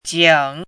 chinese-voice - 汉字语音库
jing3.mp3